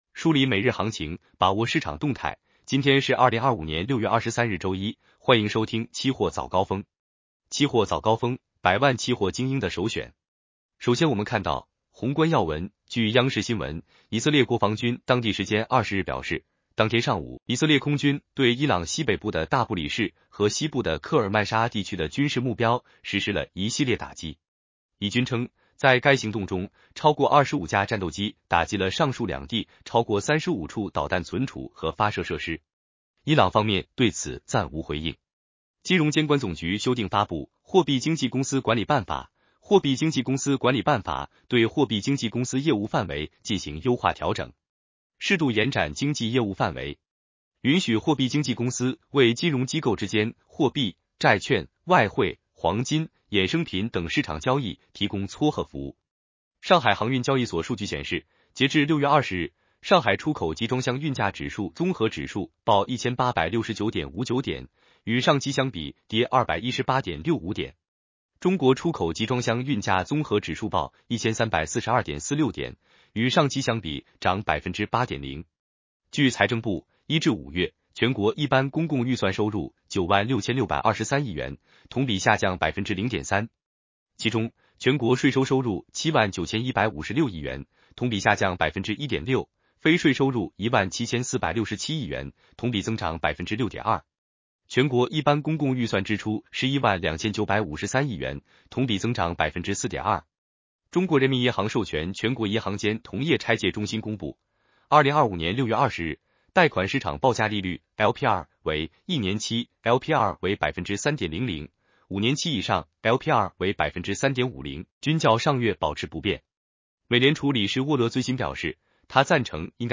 期货早高峰-音频版 男生普通话版 下载mp3 宏观要闻 1.